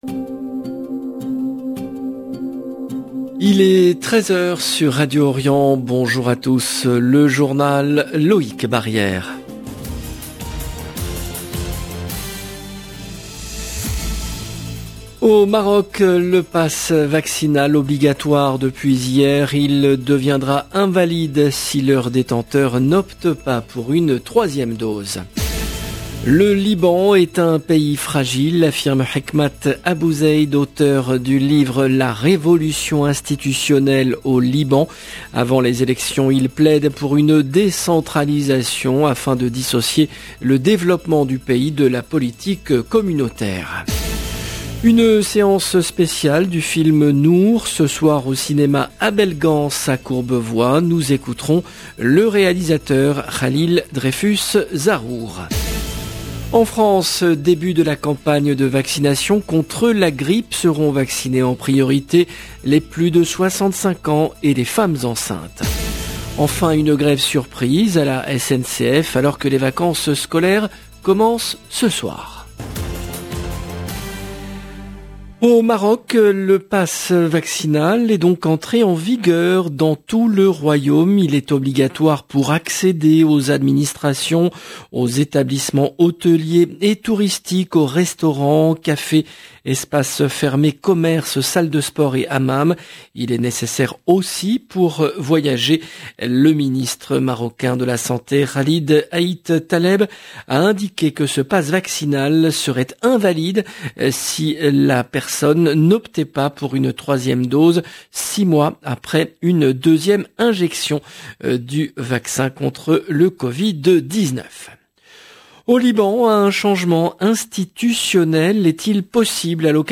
LE JOURNAL DE 13h EN LANGUE FRANÇAISE DU 22/10/21